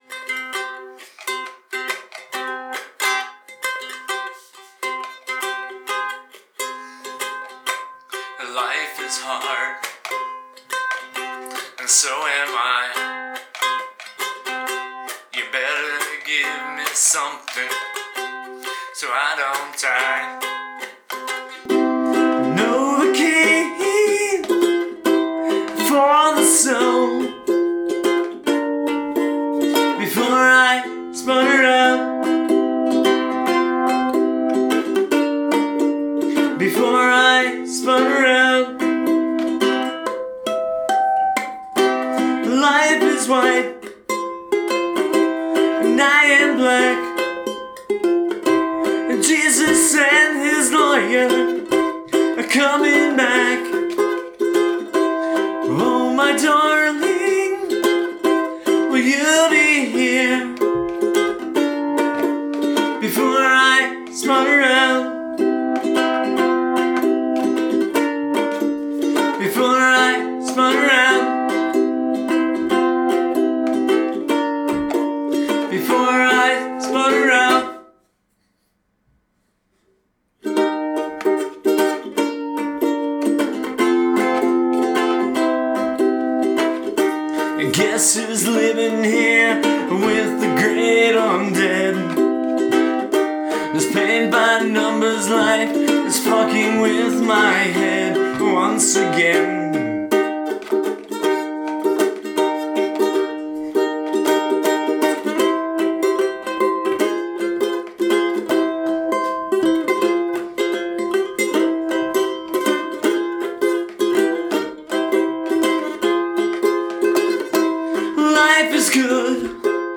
Latest Ukulele Practice Tunes